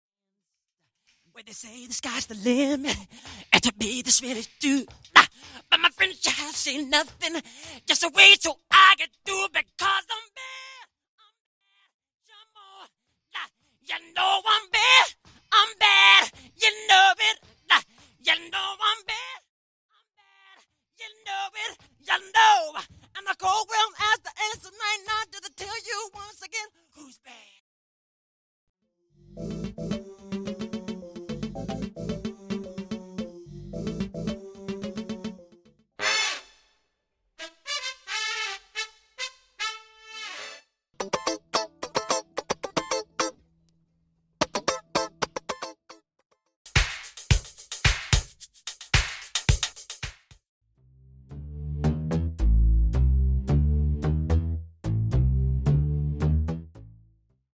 Studio Backing Vocals Stem
Studio Bassline Stem
Studio Guitar Stem
Studio Horns Stem
Studio Leading Vocals Stem
Studio Percussion & Drums Stem